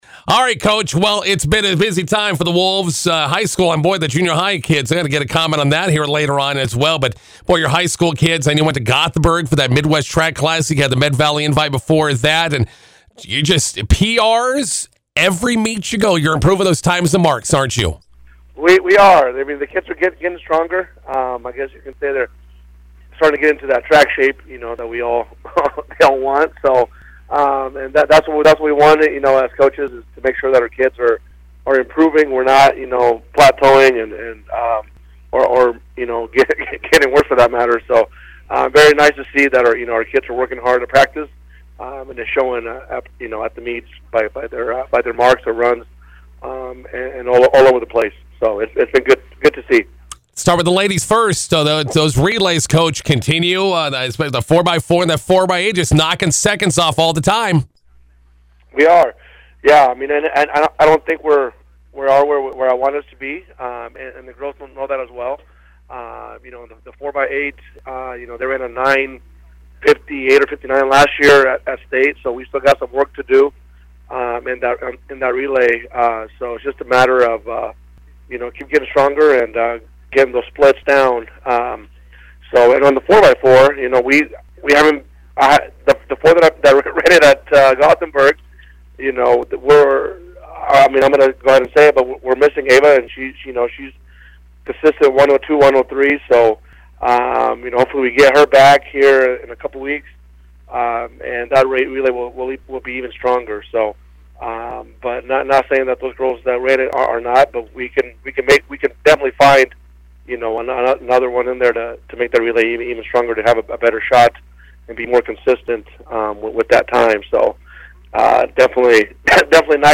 INTERVIEW: Maywood-Hayes Center Track and Field teams prep for next week's RPAC Conference meet with a road trip to Hershey today.